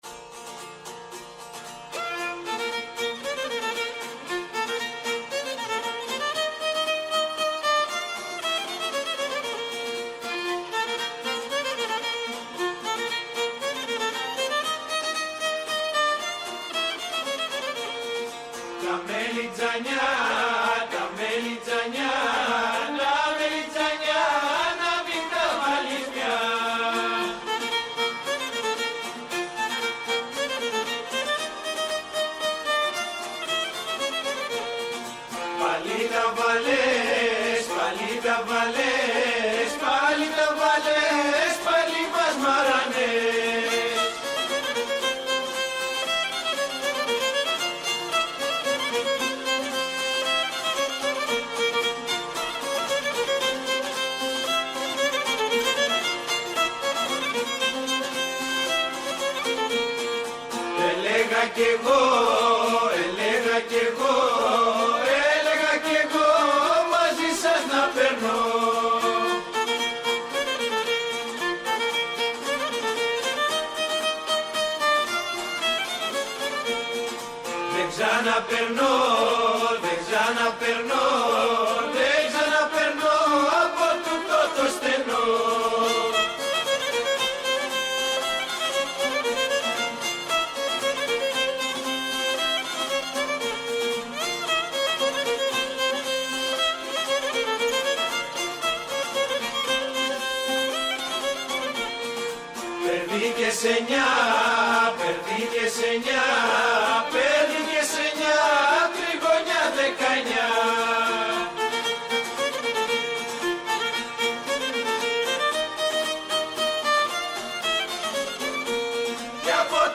Ένα ραδιοφωνικό ταξίδι που ξανάφερε στην επιφάνεια μνήμες, μύθους και όνειρα του Αιγαίου.
Οι ακροατές γνώρισαν τρεις φωνές που καθρεφτίζουν την ψυχή του νησιού: